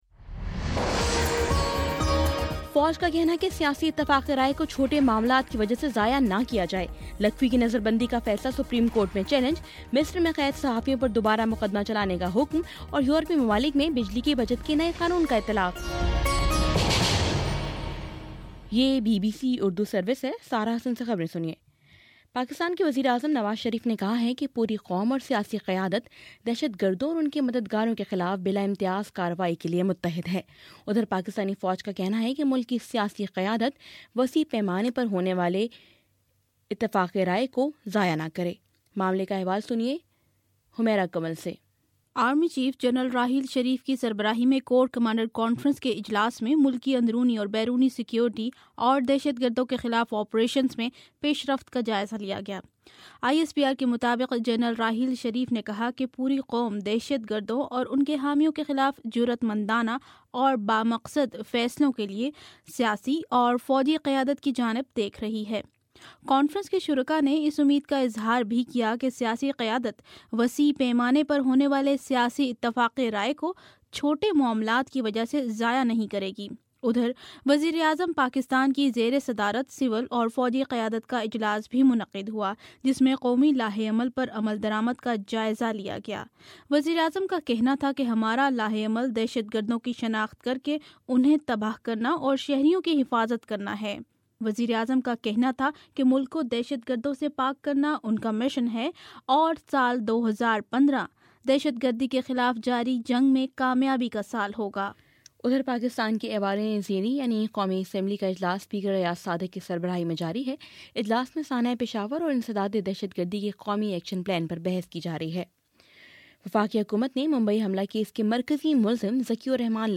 جنوری 01: شام سات بجے کا نیوز بُلیٹن